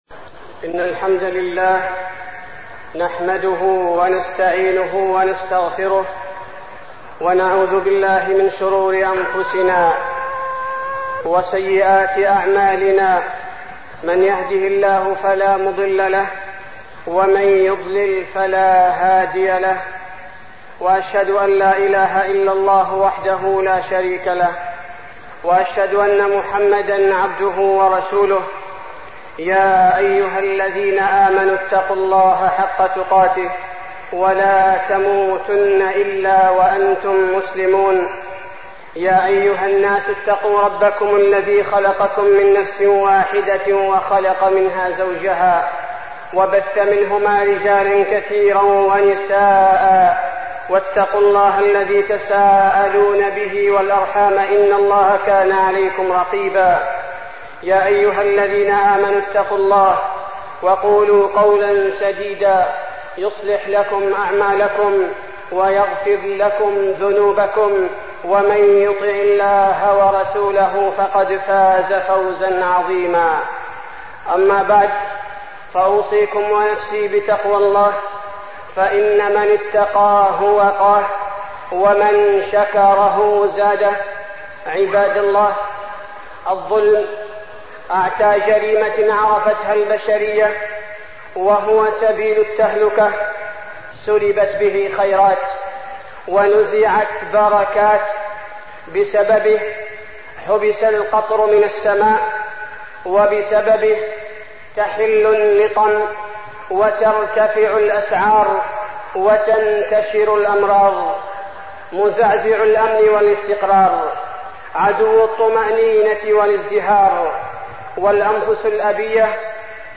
تاريخ النشر ١٠ شعبان ١٤١٧ هـ المكان: المسجد النبوي الشيخ: فضيلة الشيخ عبدالباري الثبيتي فضيلة الشيخ عبدالباري الثبيتي الظلم